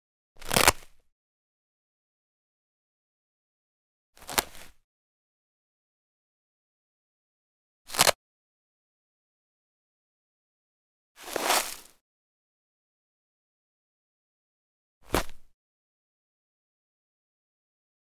Camera Small Canvas Case Open Velcro Flap Sound
household